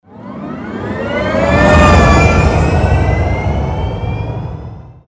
mit 6 coolen Motorgeräuschen